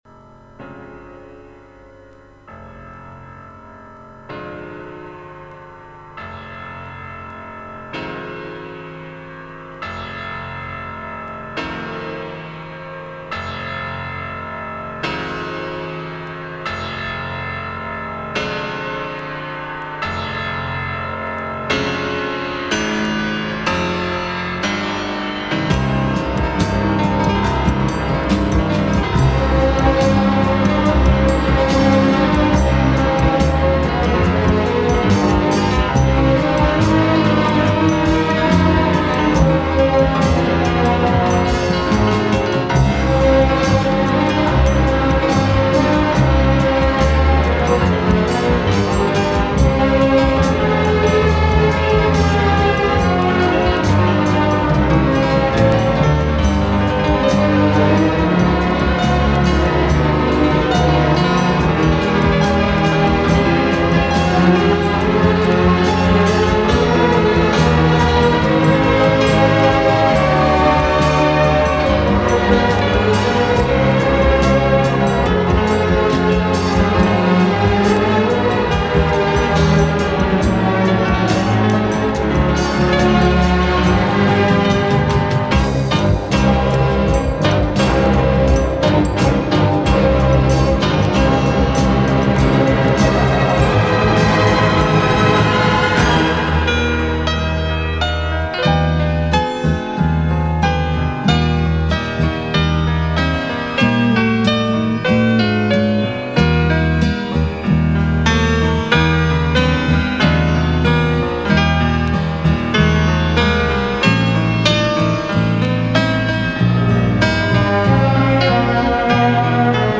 改编水平非常高，较之我们